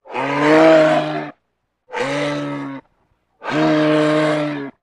Moose Grunts, Multiple. Close Perspective.